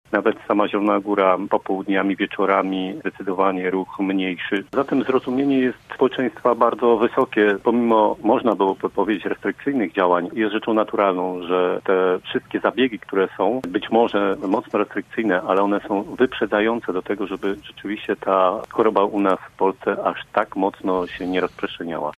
Koronawirus zdominował dzisiejsze Polityczne Podsumowanie Tygodnia. Nasi goście, z którymi ze względu na zagrożenie epidemiologiczne wyjątkowo łączyliśmy się telefonicznie oceniali działania rządu i reakcję społeczeństwa w obliczu pandemii.
Zdecydowana większość społeczeństwa rozumie tę sytuację uważa przewodniczący Rady Miasta Piotr Barczak (PiS):